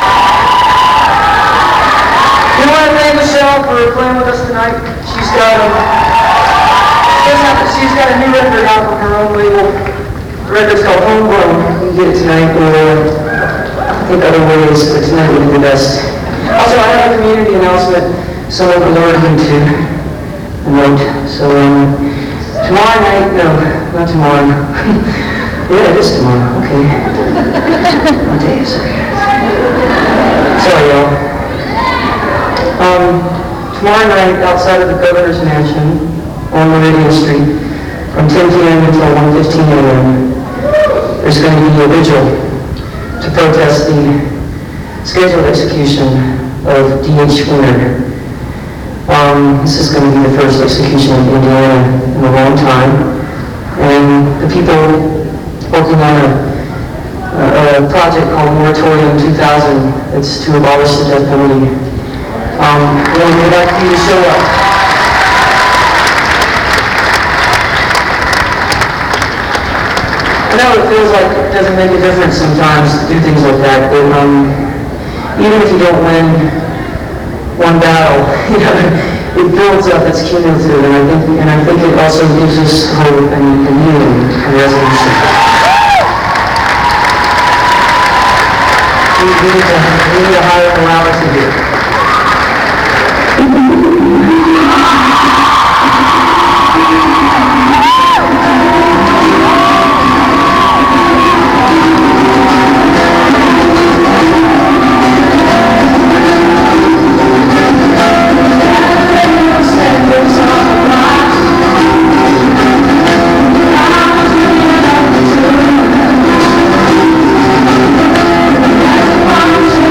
(this recording has some distortion)